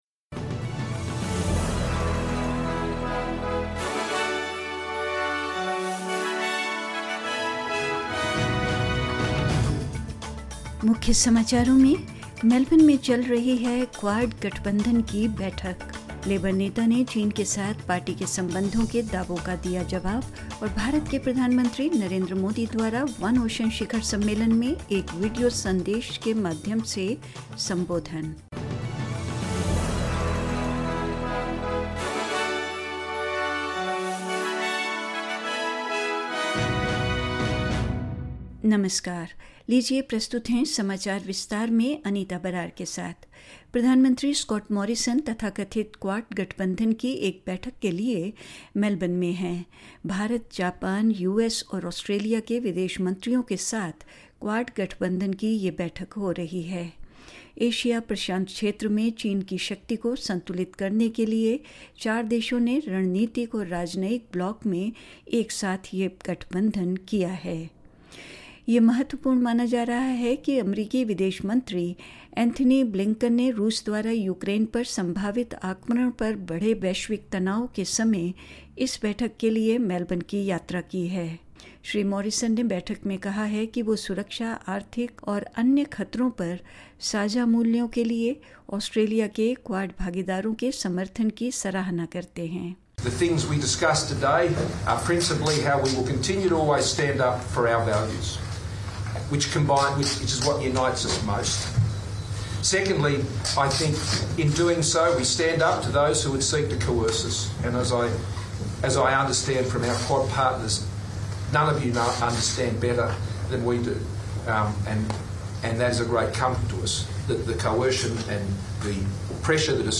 In this SBS Hindi bulletin: A meeting of the so-called "Quad" alliance gets underway in Melbourne; The Labor Leader responds to claims about his party's relationship with China; Indian PM Narendra Modi will address the One Ocean Summit through a video message and more news